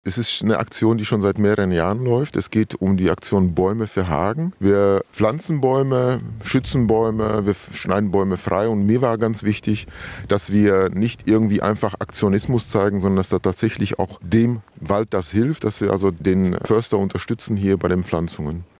oton-warum-die-rotarier-baeume-pflanzen.mp3